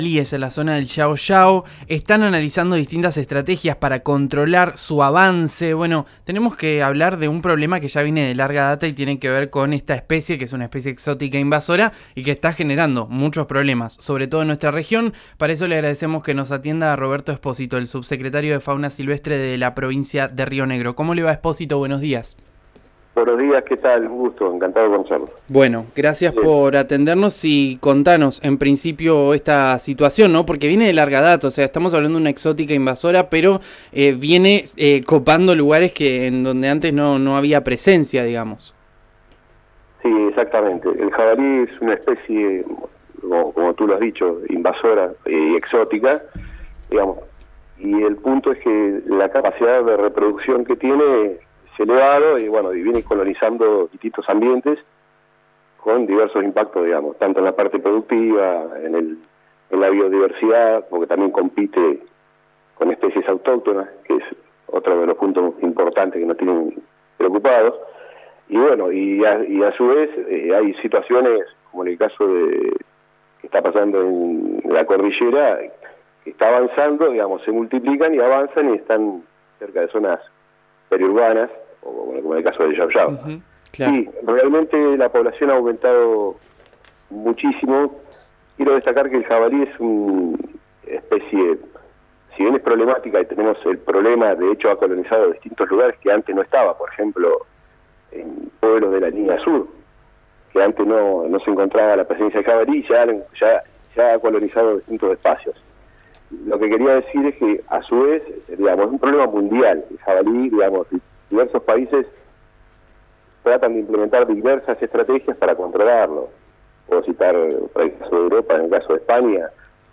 Escuchá a Roberto Espósito. Subsecretario de Fauna Silvestre de Río Negro en diálogo con RÍO NEGRO RADIO: